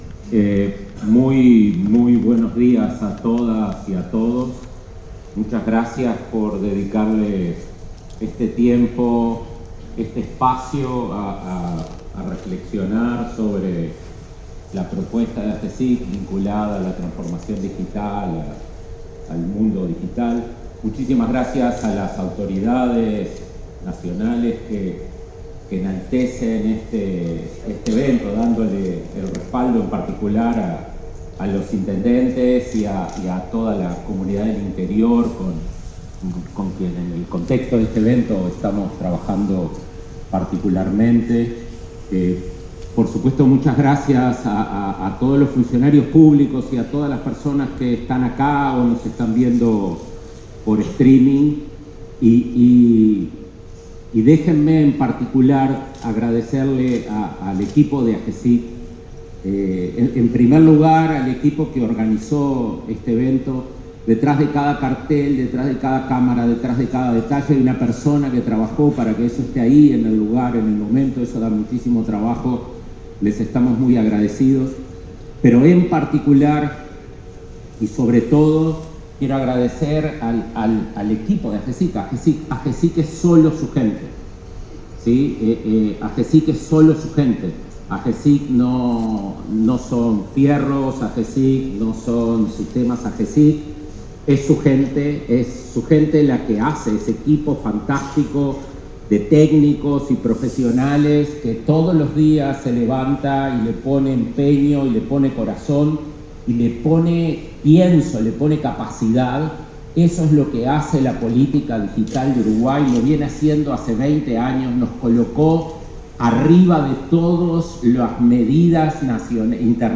Palabras del director de Agesic, Daniel Mordecki
Palabras del director de Agesic, Daniel Mordecki 26/11/2025 Compartir Facebook X Copiar enlace WhatsApp LinkedIn En el marco del evento anual de la Agencia de Gobierno Electrónico y Sociedad de la Información y del Conocimiento (AGESIC) denominado "El mundo es digital", su director, Daniel Mordecki, dio inicio a la actividad.